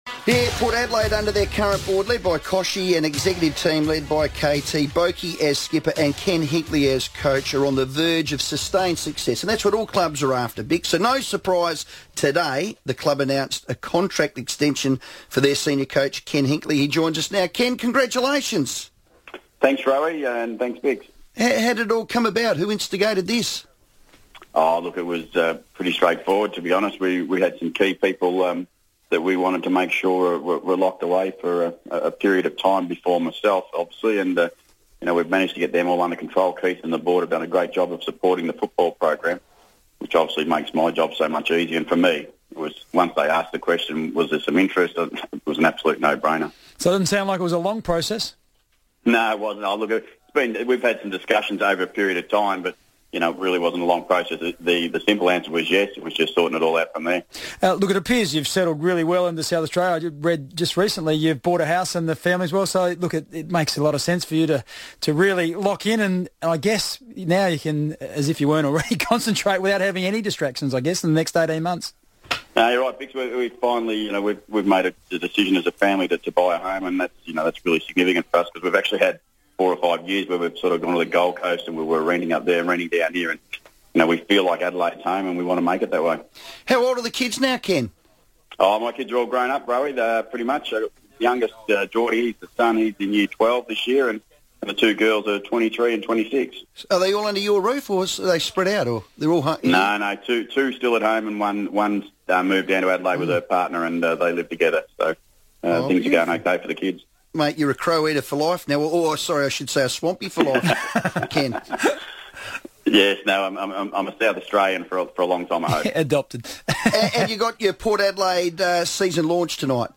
Ken Hinkley FIVEaa interview - Thursday 26 March, 2015